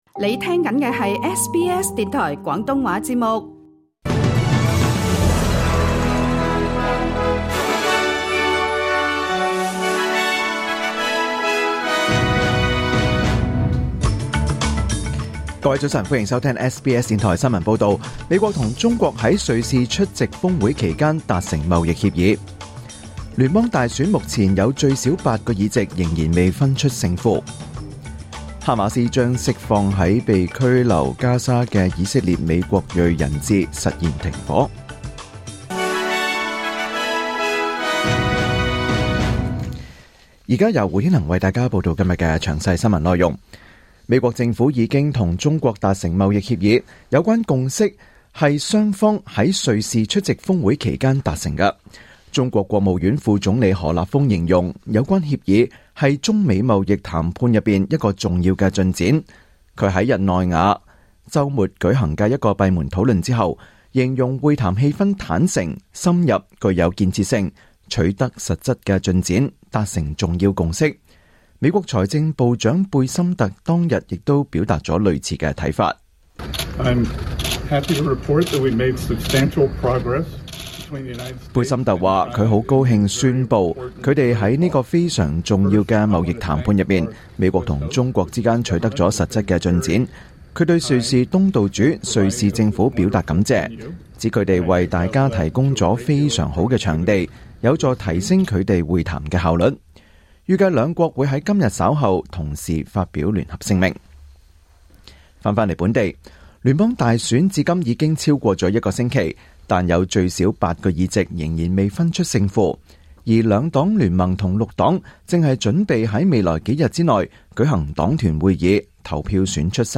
2025年5月12日SBS 廣東話節目九點半新聞報道。